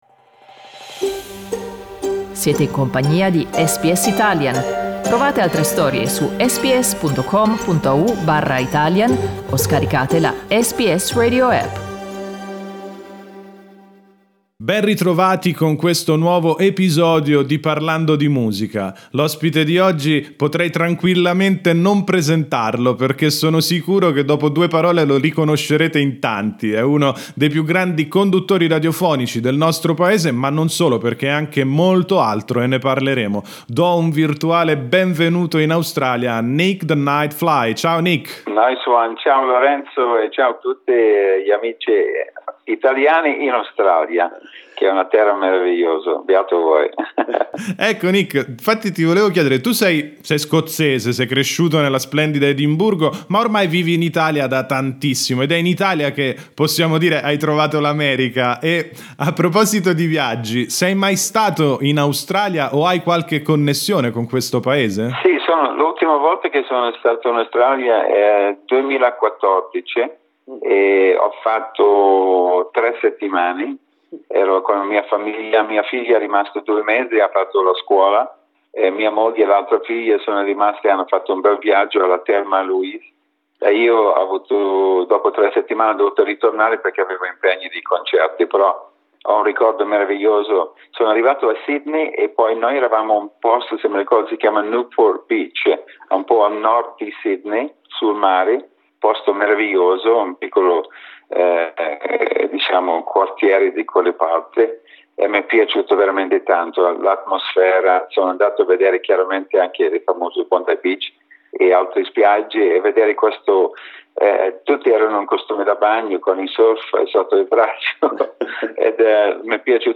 Riascolta qui l'intervista a Nick The Nightfly: